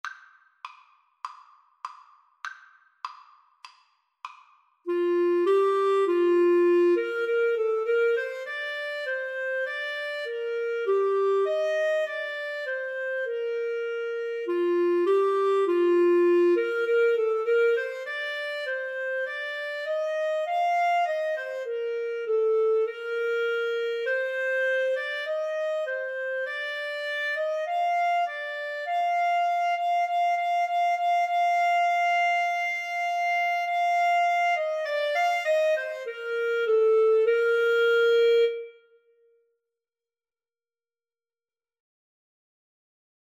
ClarinetAlto Saxophone
4/4 (View more 4/4 Music)